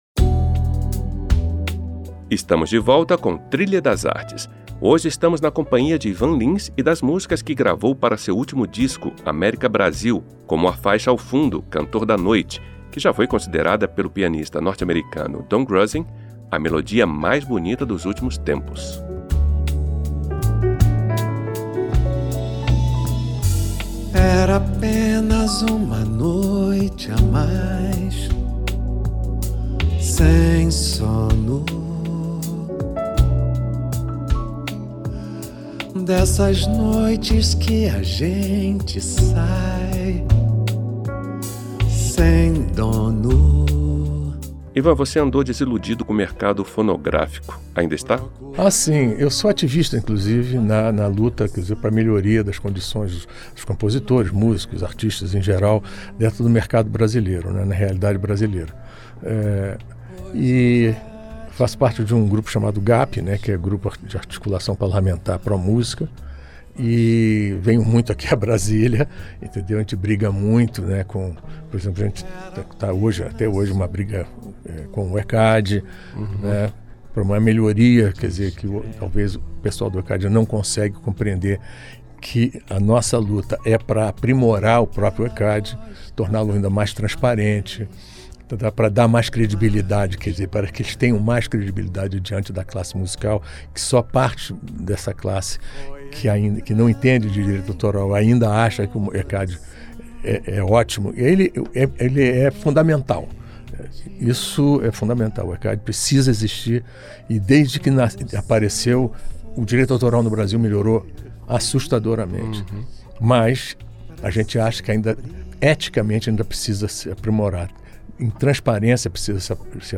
O programa mistura música, informação e opinião, além de trazer entrevistas com grandes artistas brasileiros.